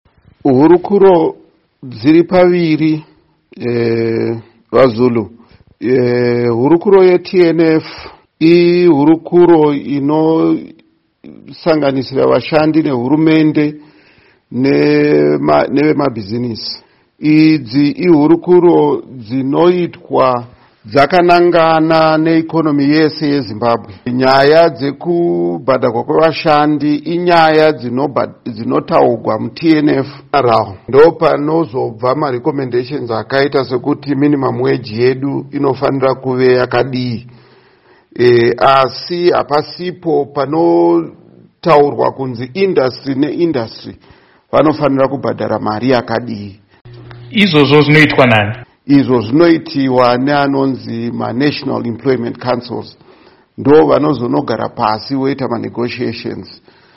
Hurukuro naMuzvinafundo Paul Mavima